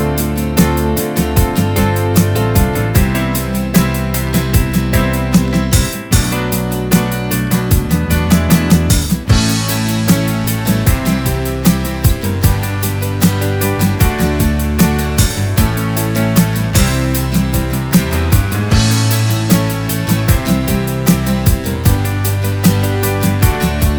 no Backing Vocals Soul / Motown 3:12 Buy £1.50